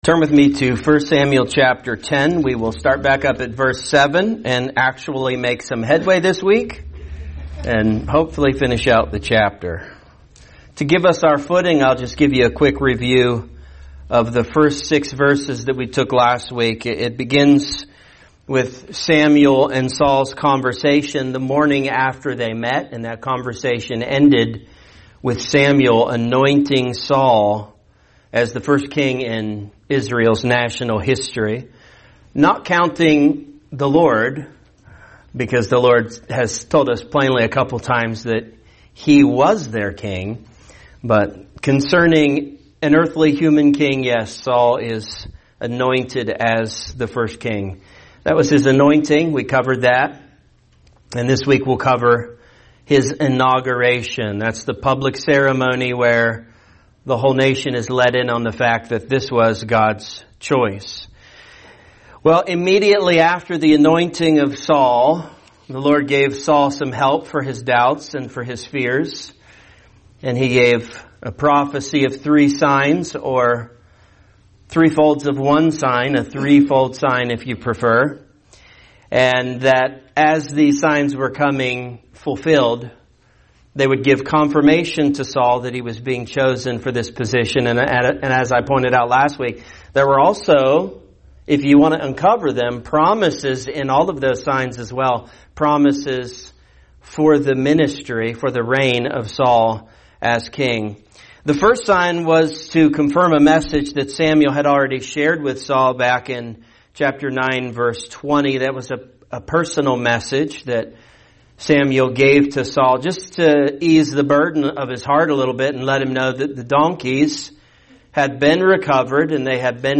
A message from the topics "The Book of 1 Samuel."